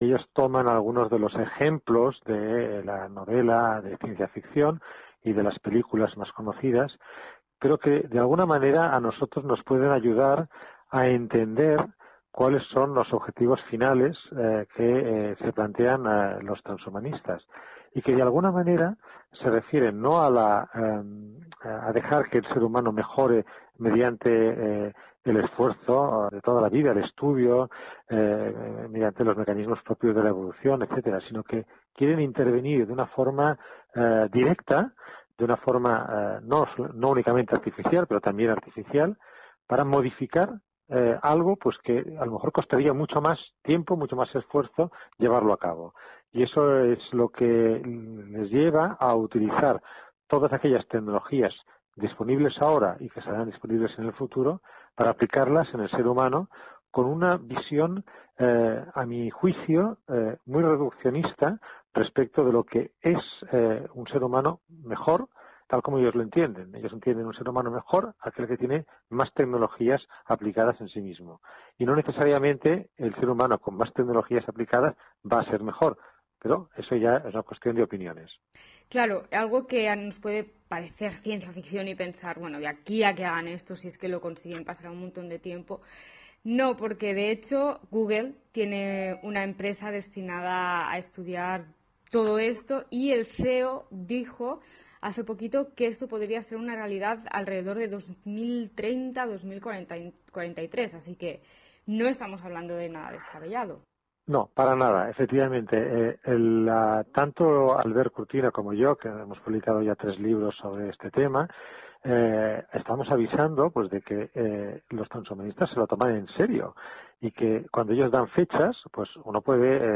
ha entrevistado